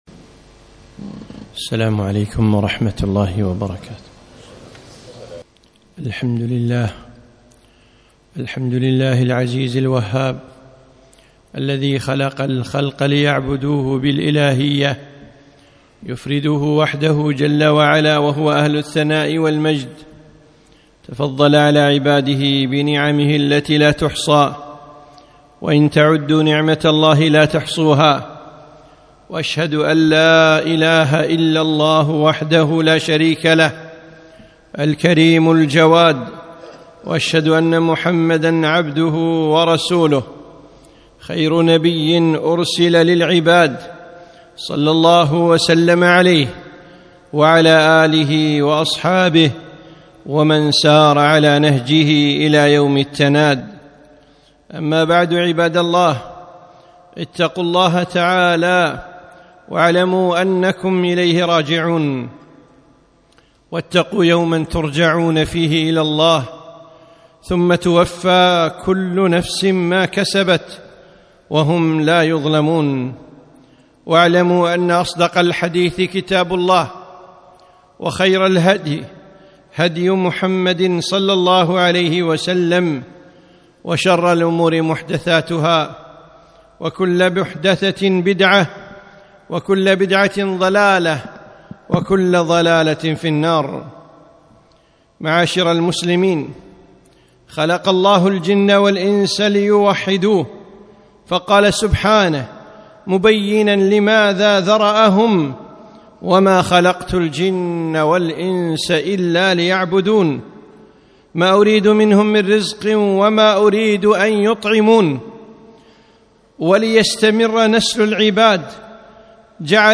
خطبة - العقد الوثيق